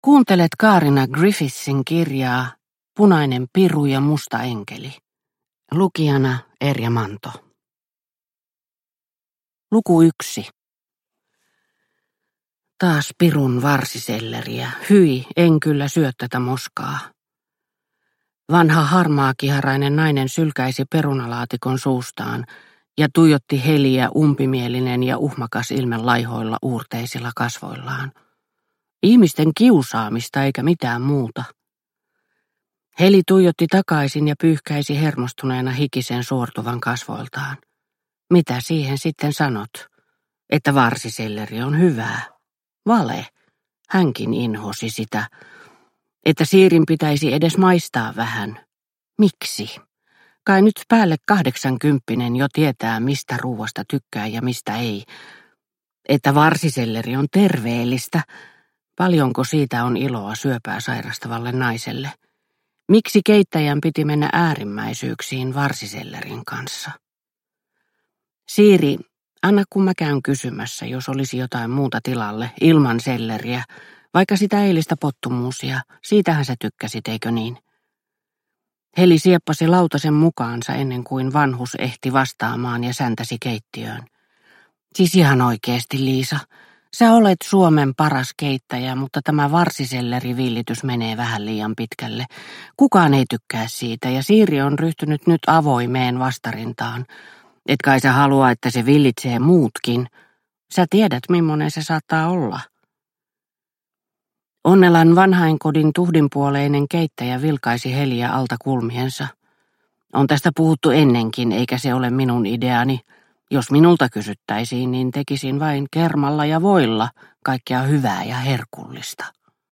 Punainen piru ja musta enkeli – Ljudbok – Laddas ner